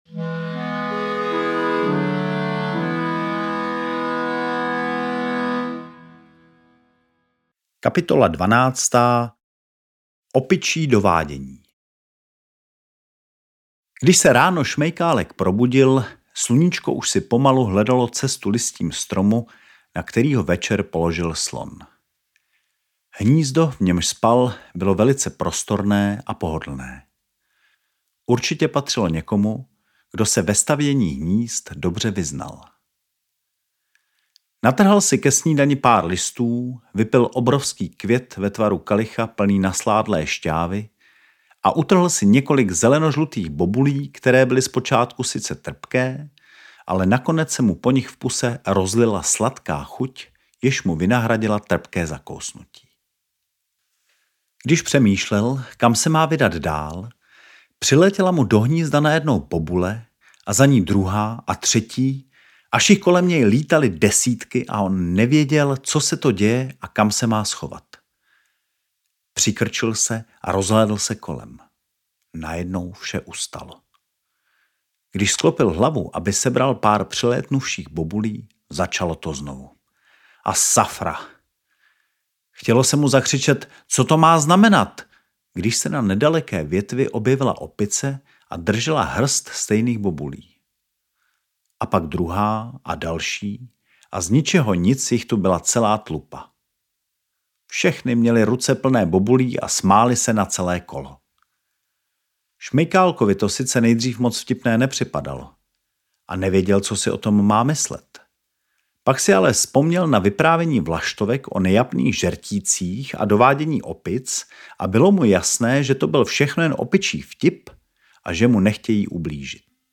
Na této stránce si můžete zdarma stáhnout nebo poslechnout audio verzi knihy po jednotlivých kapitolách nebo jako celek.